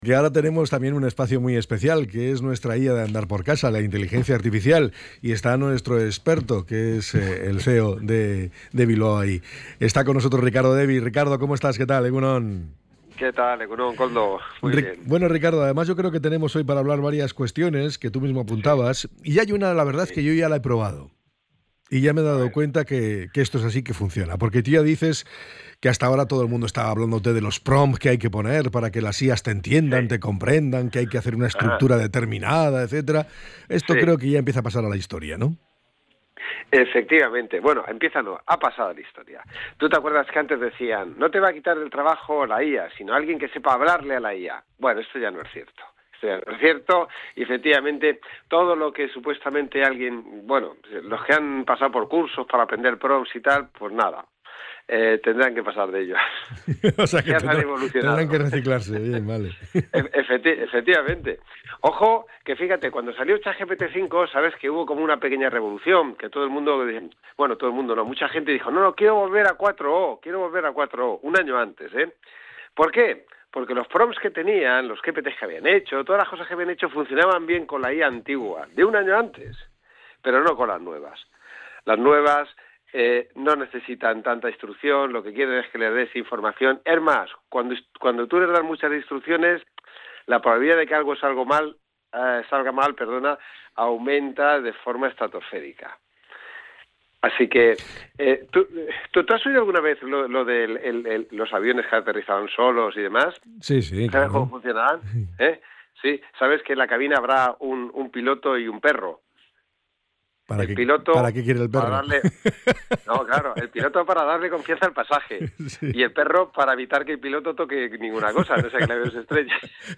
Podcast Tecnología
El invitado ha introducido el concepto de skills como capacidades que la IA ha incorporado según necesidad.